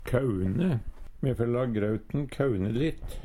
DIALEKTORD PÅ NORMERT NORSK kaune kjølne Infinitiv Presens Preteritum Perfektum kaune kauna kauna kauna Eksempel på bruk Me fær la grauten kaune litt.